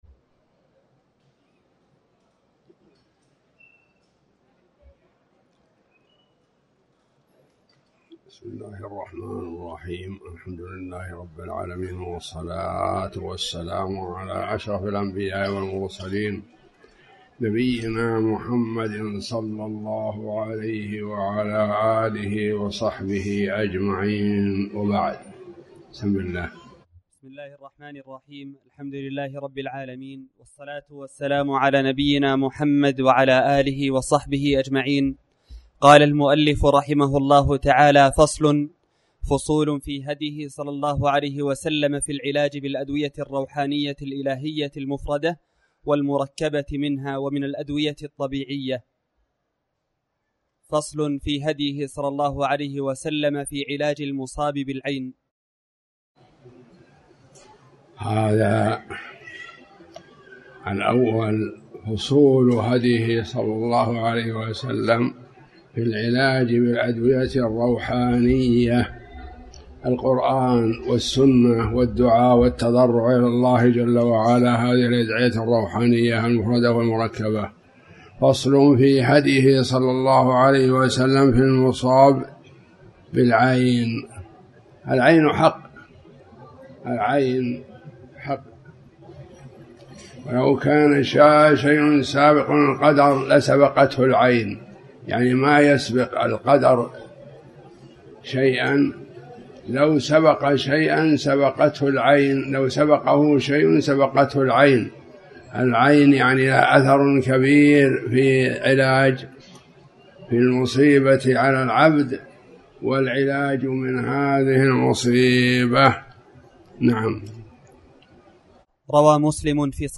تاريخ النشر ٢٢ شوال ١٤٣٩ هـ المكان: المسجد الحرام الشيخ